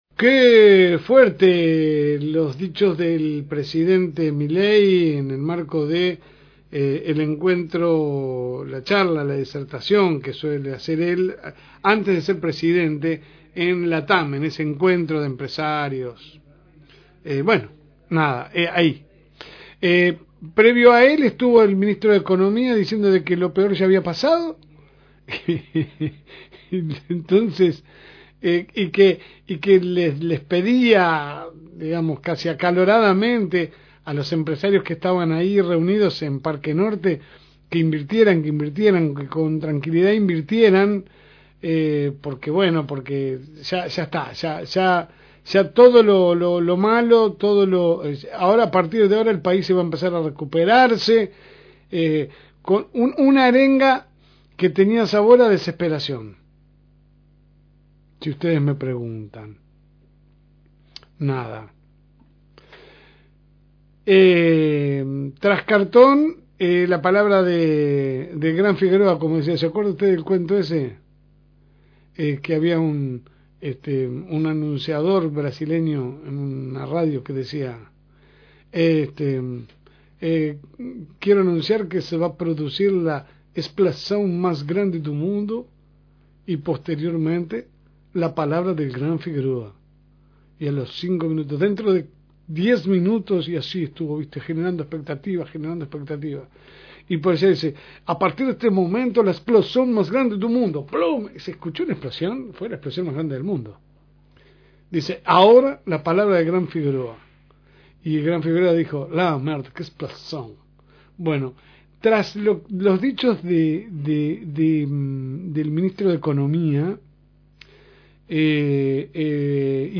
AUDIO – Editorial – FM Reencuentro